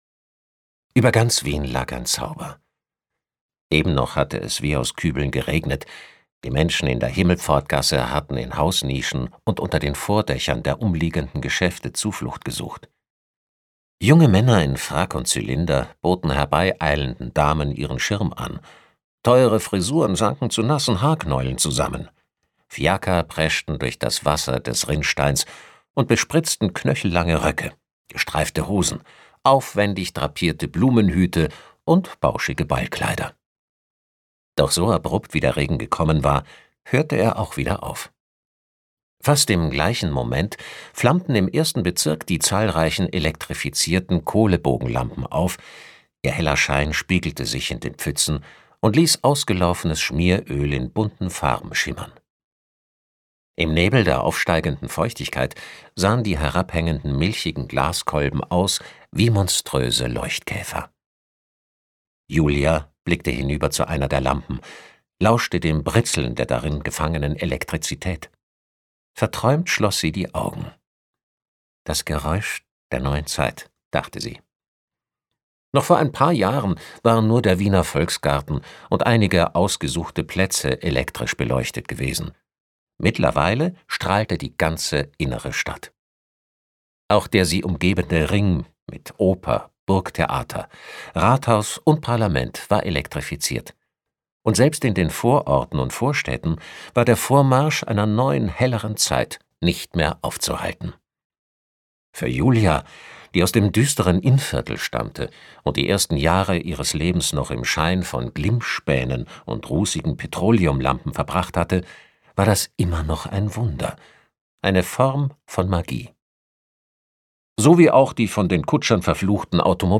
Die Sprecher-Stimme ist echt toll, sympathische Stimme, gutes Timbre.